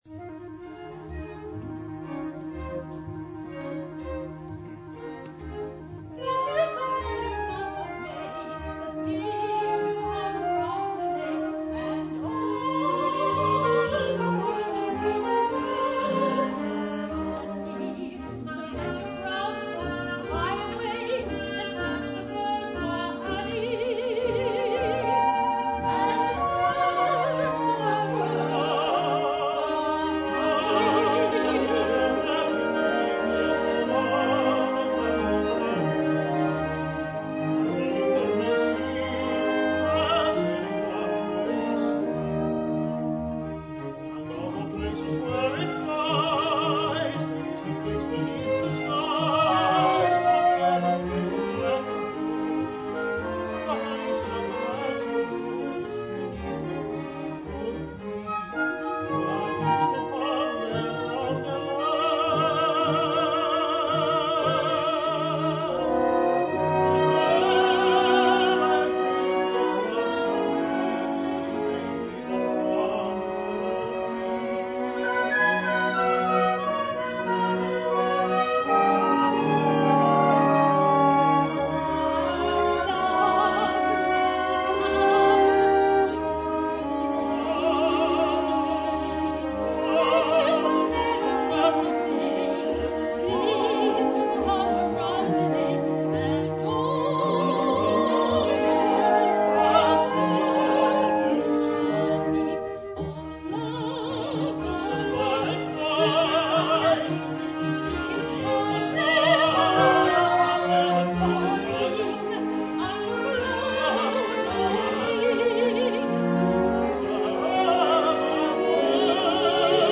Jeeves Audio Services is pleased to be associated with the Gilbert and Sullivan Society of Victoria, making live recordings of the society's productions.